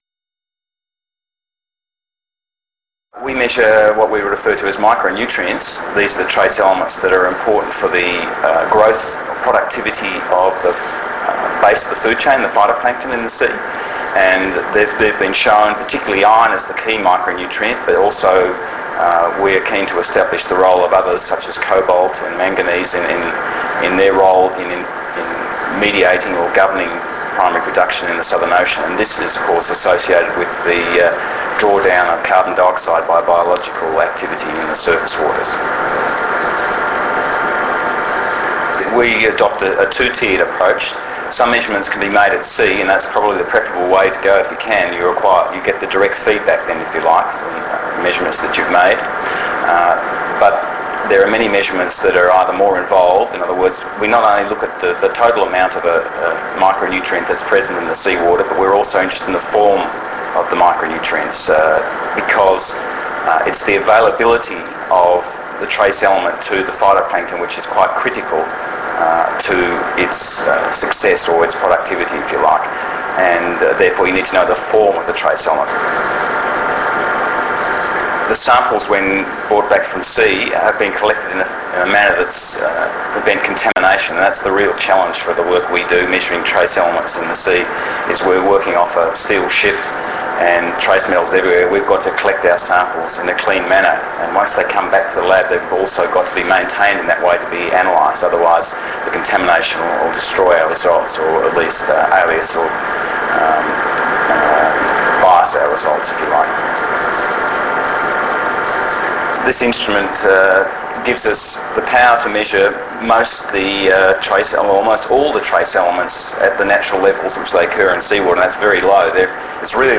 Scientist Interviews